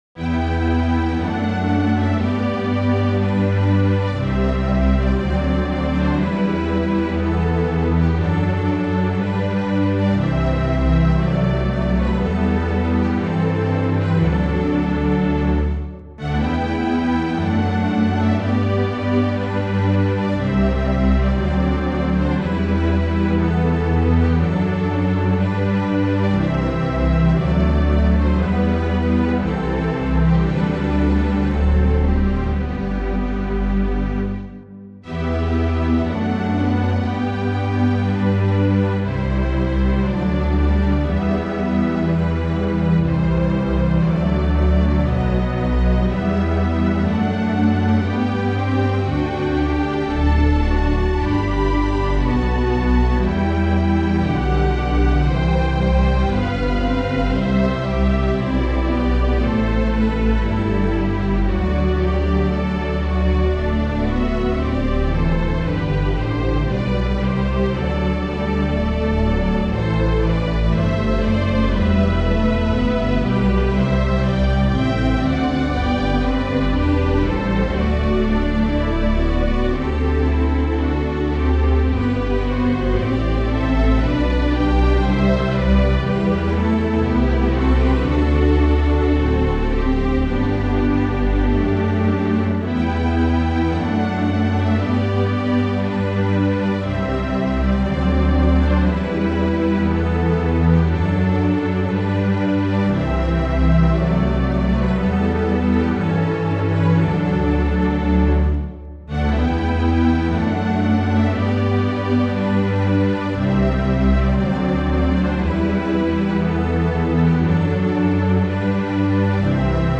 Quatuor symphonique de cordes à la manière baroque : Violons, Alti, Violoncelles et Contrebasses.